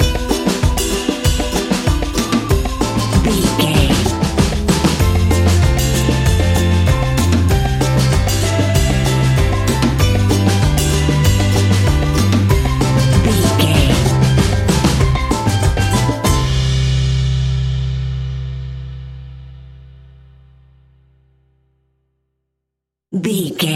Uplifting
Ionian/Major
steelpan
happy
drums
percussion
bass
brass
guitar